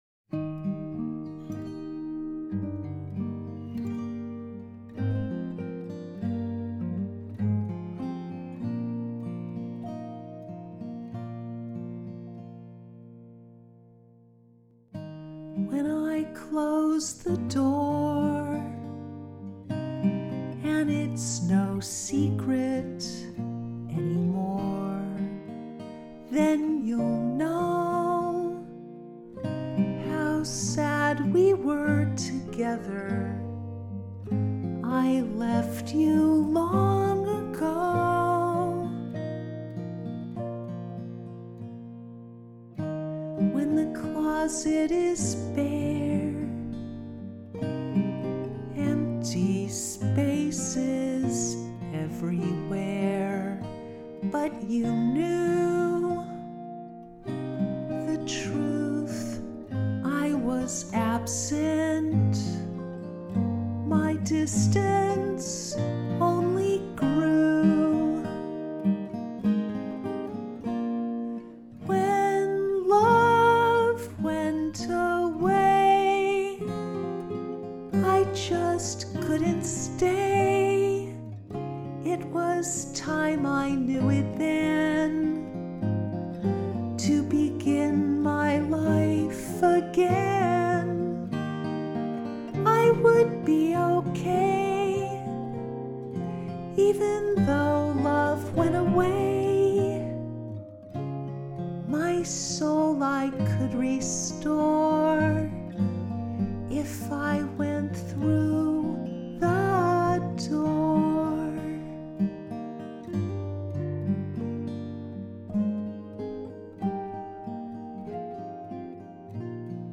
the-door-voc-gtr-6-13-24.mp3